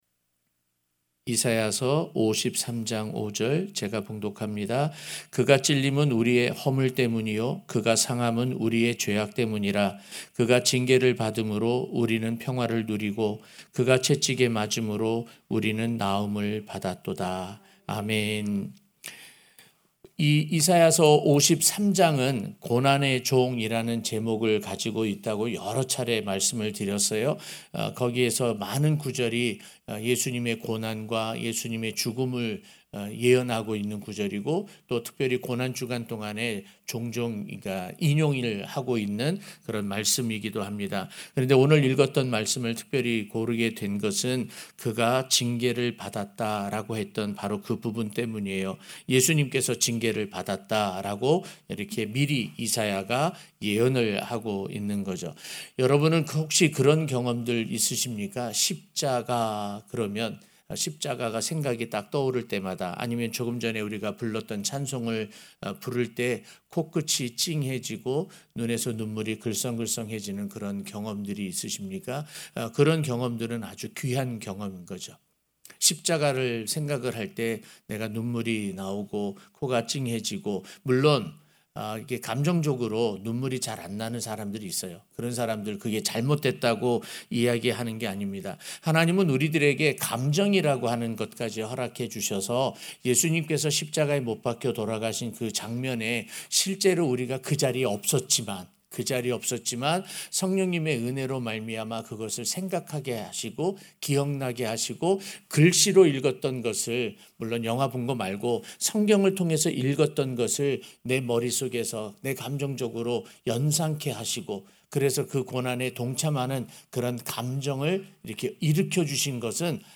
고난주간설교 2-그가 징계를 받음으로 (사 53:5)